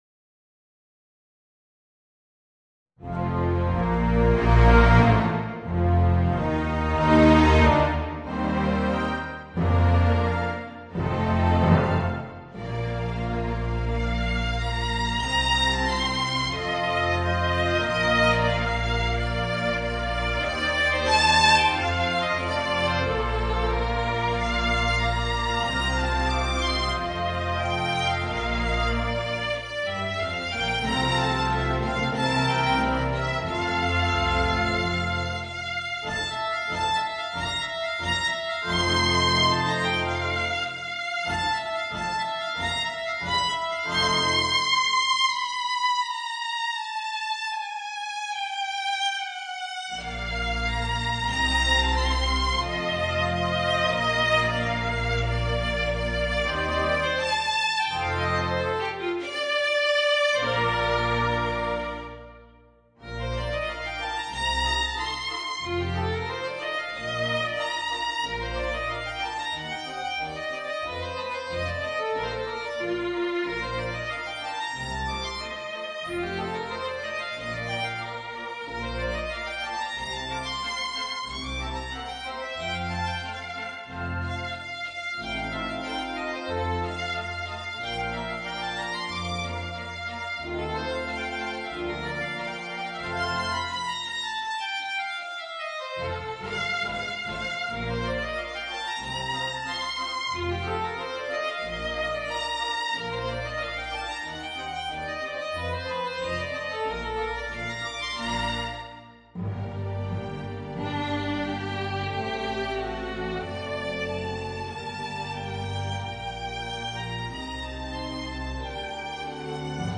Voicing: Oboe and Orchestra